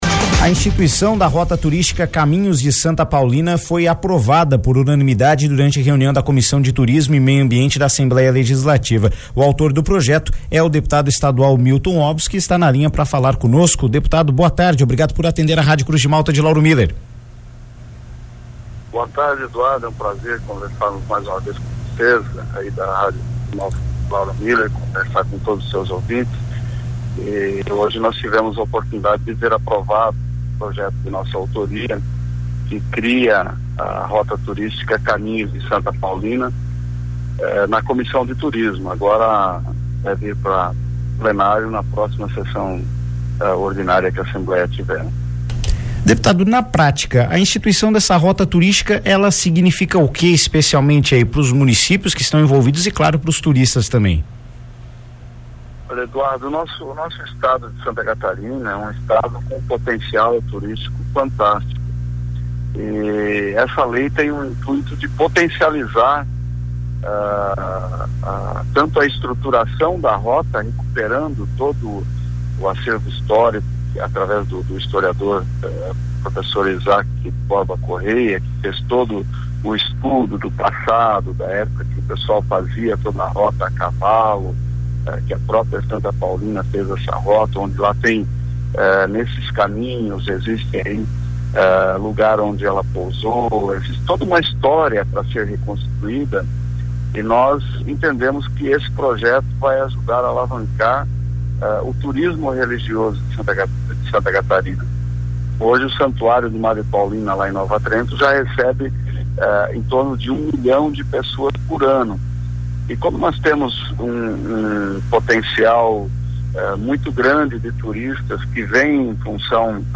O projeto que institui a Rota Turística Caminhos de Santa Paulina foi aprovado pela Comissão de Turismo e Meio Ambiente da Assembleia Legislativa e está pronto para ser votado em plenário. O deputado estadual Milton Hobus (PSD) afirmou, em entrevista por telefone ao Jornal das Cinco desta quarta-feira (15), que a institucionalização do projeto vai potencializar a criação de um turismo positivo para a região que abrange os municípios de Nova Trento, São João Batista, Tijucas, Canelinha e Camboriú.